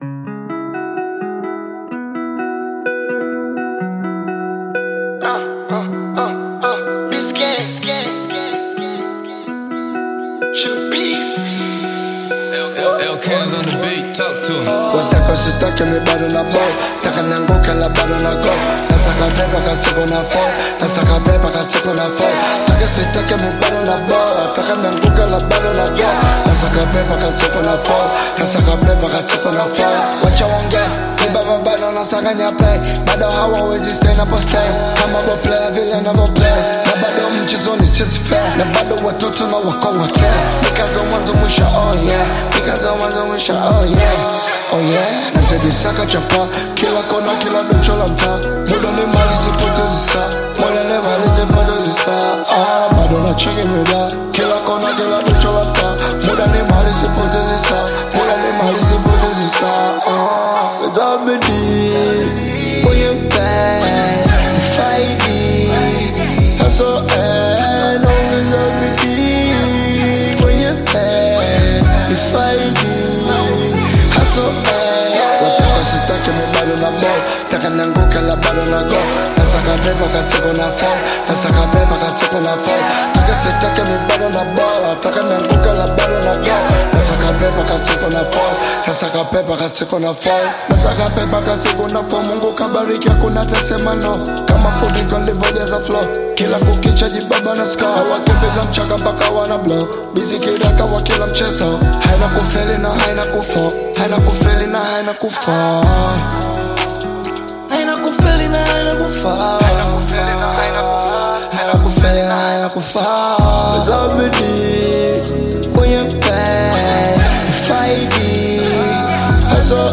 Trap Hip-Hop song
African Music